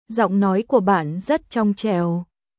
ゾン ノイ クア バン ザット チョン チャオ🔊